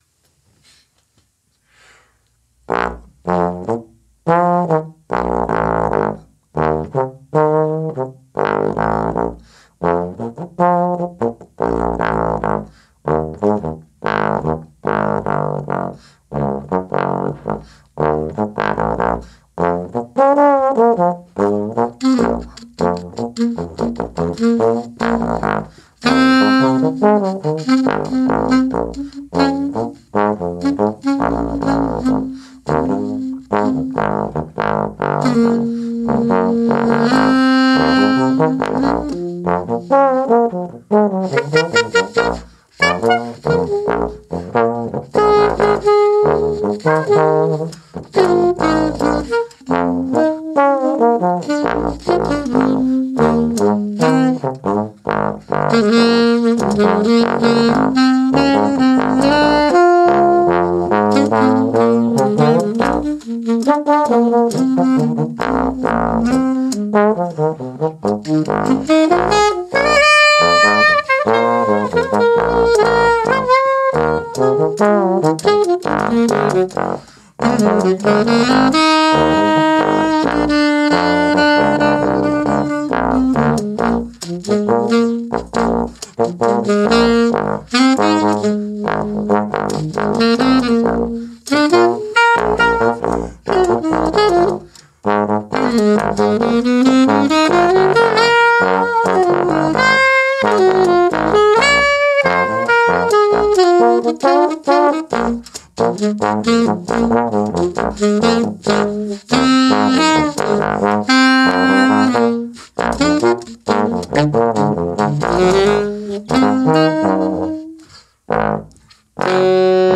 im Atelier